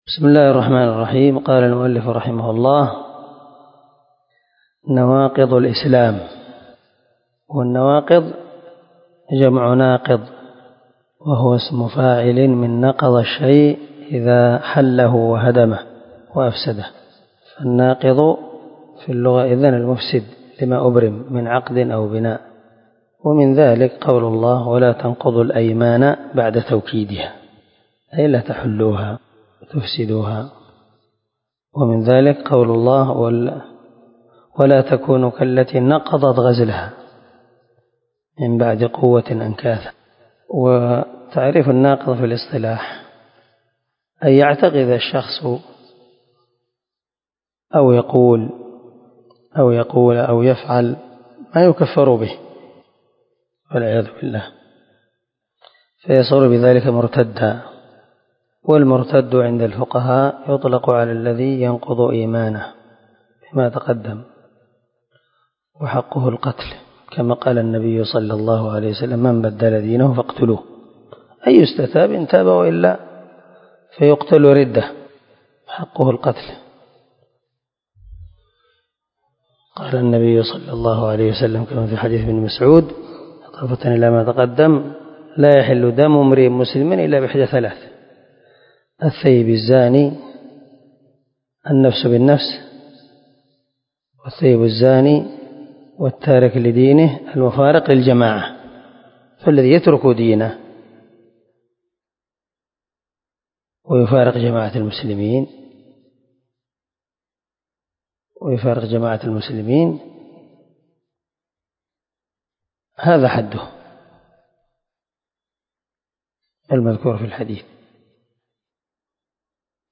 🔊الدرس 11 الناقض الأول ( من شرح الواجبات المتحتمات)